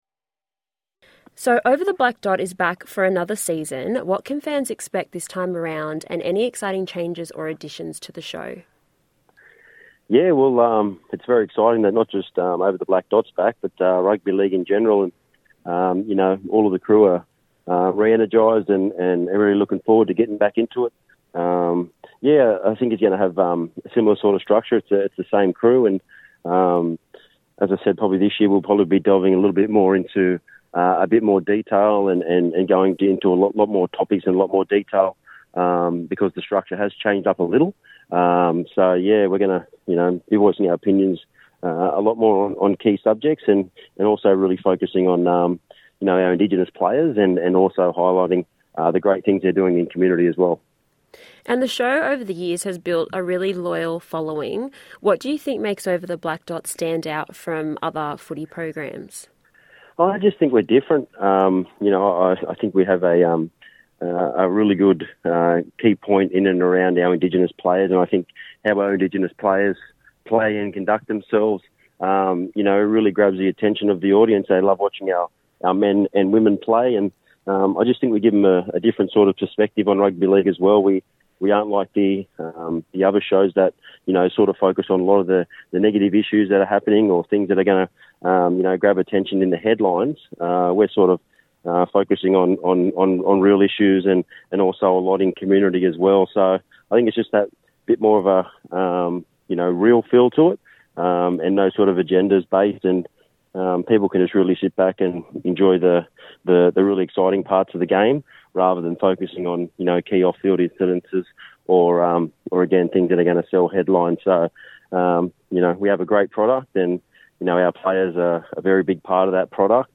Over The Black Dot, NITV’s much-loved rugby league show, is back for another season, and co-host Beau Champion joined NITV Radio to chat about what fans can expect.